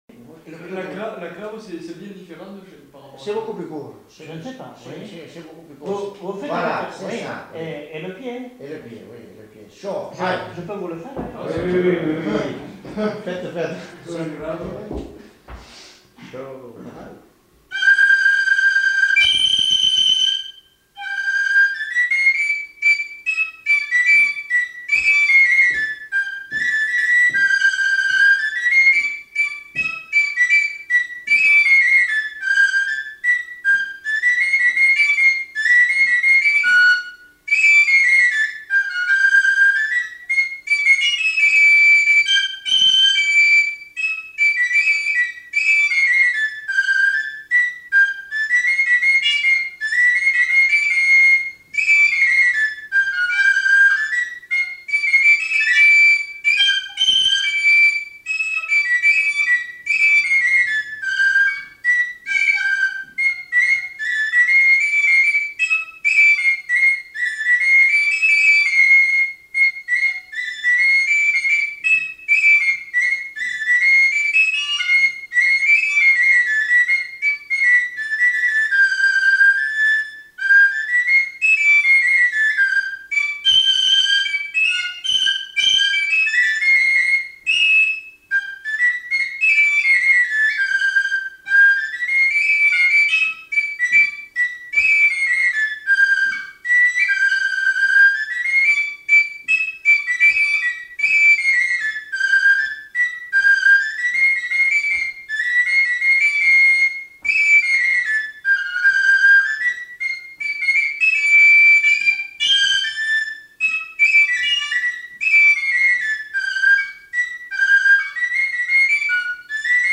Aire culturelle : Pays Basque
Lieu : Trois-Villes
Genre : morceau instrumental
Instrument de musique : txistu
Danse : craba